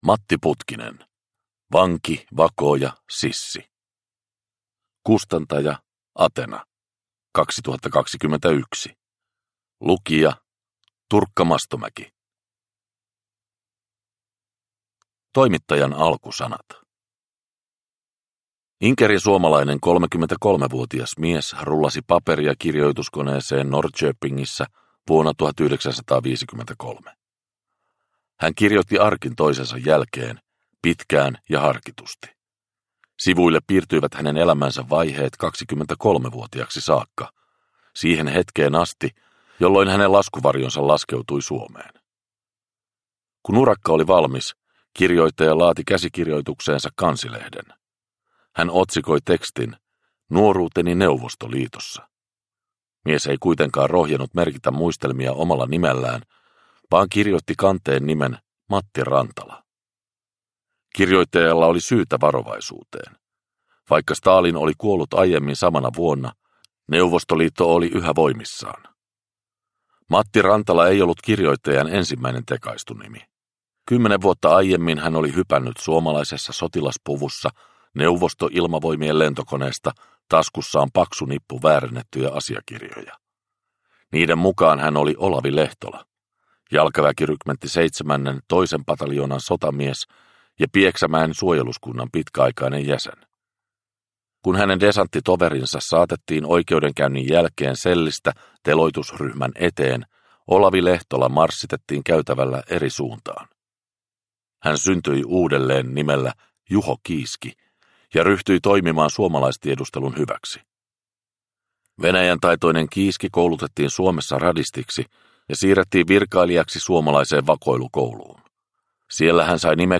Vanki, vakooja, sissi – Ljudbok – Laddas ner